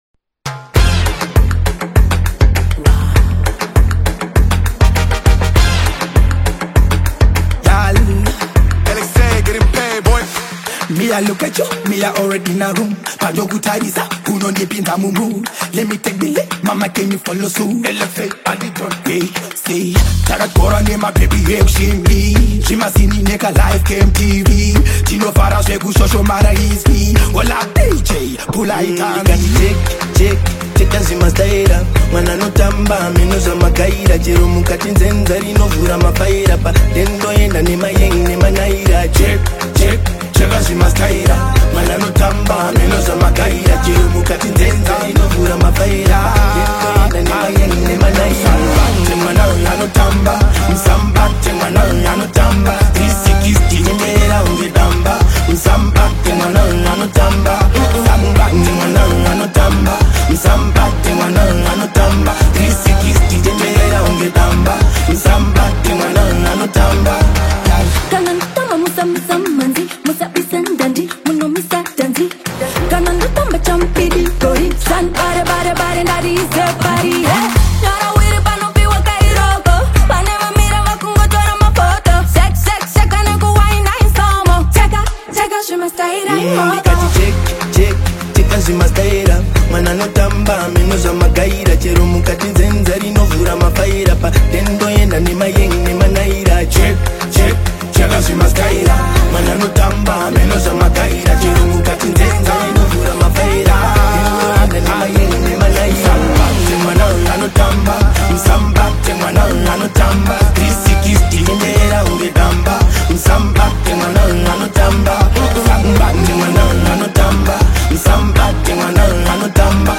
Afro-Dancehall/Afro-Pop
contemporary Afro-urban sound